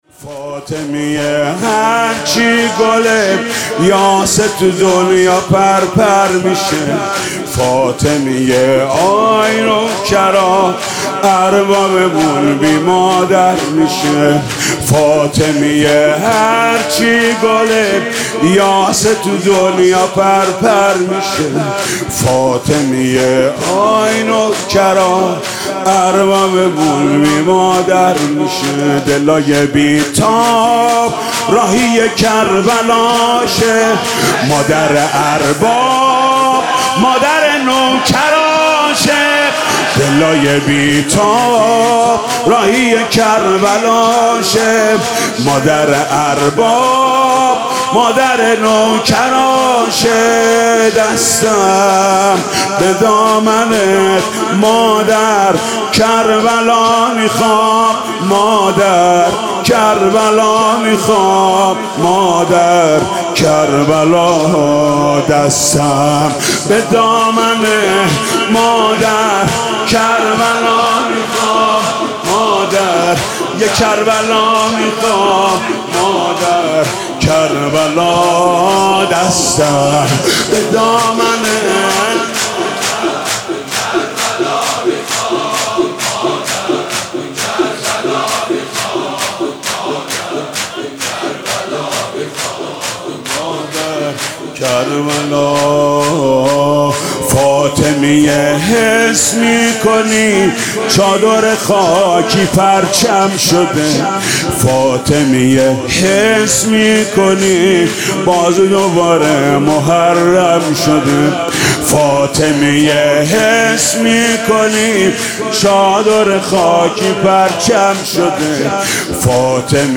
متن سینه زنی شور دهه فاطمیه1401